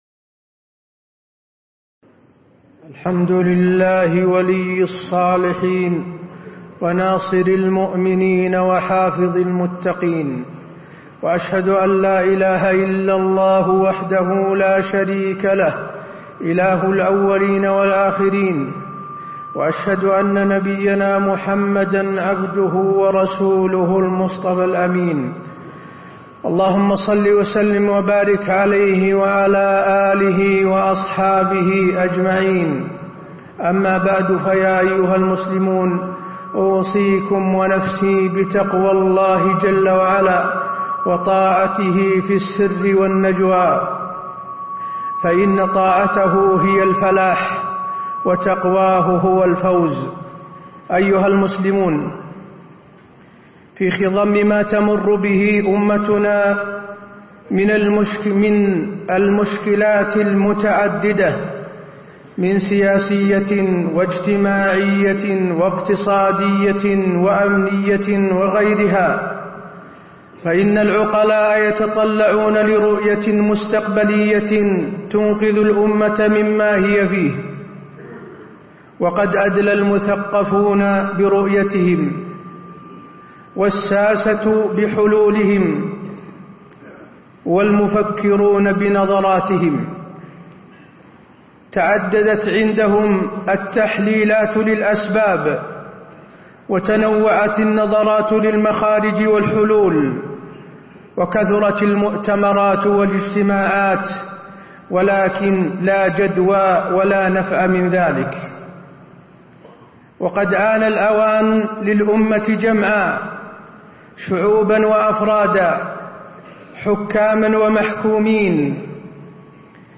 تاريخ النشر ٧ ربيع الأول ١٤٣٧ هـ المكان: المسجد النبوي الشيخ: فضيلة الشيخ د. حسين بن عبدالعزيز آل الشيخ فضيلة الشيخ د. حسين بن عبدالعزيز آل الشيخ الوثيقة التي أضاعها المسلمون